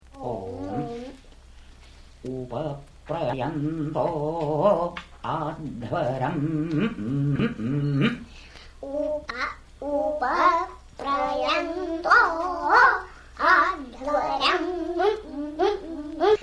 Here two young brahmacarins (students) in the Nambudiri tradition of southern India learn to recite by imitation. The emphasis bhramans have traditionally placed on exact repetition relates to the importance of preserving the "sound" of the Vedas.
This would seem especially true of the Nambudiri tradition with its characteristic wide vibrato (for want of a better description).